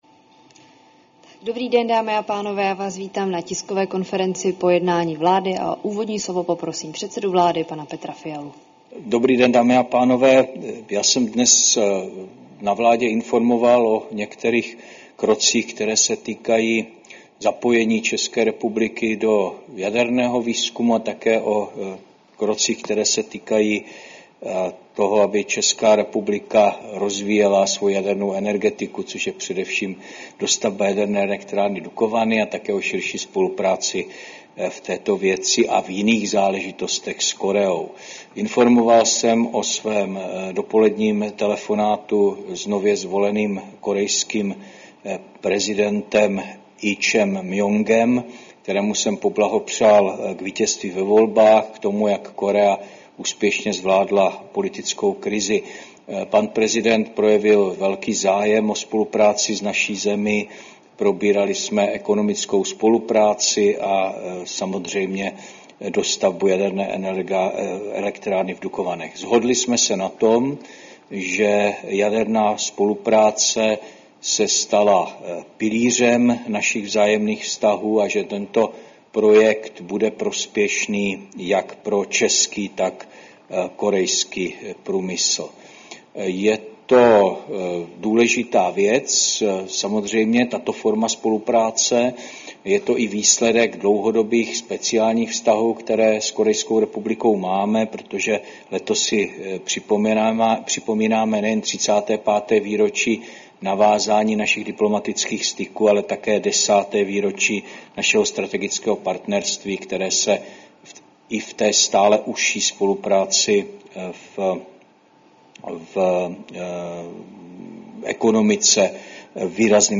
Tisková konference po jednání vlády, 11. června 2025